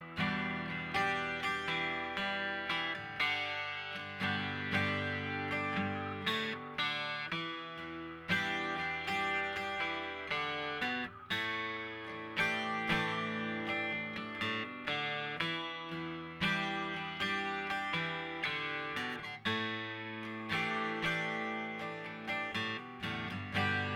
Minus 6 String Guitar Rock 4:55 Buy £1.50